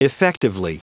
Prononciation du mot effectively en anglais (fichier audio)
Prononciation du mot : effectively